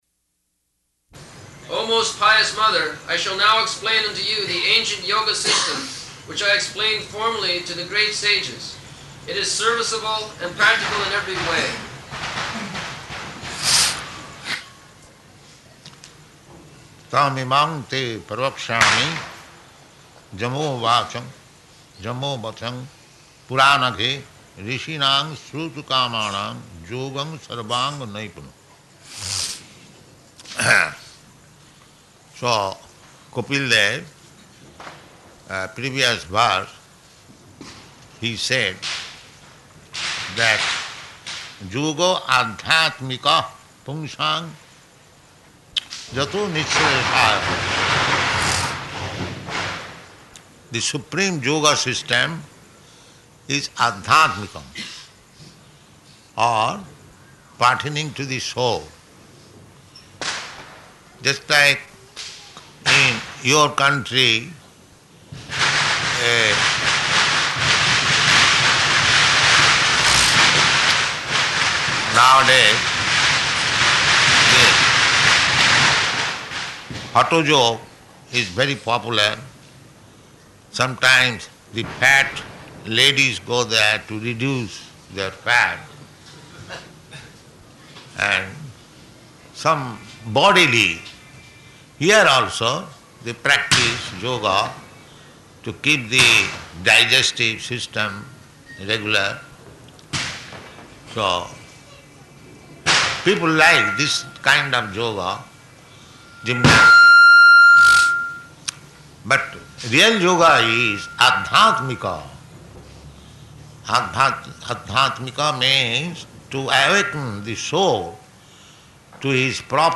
Location: Bombay
[loud sound of fireworks, firecrackers, etc., going on in background]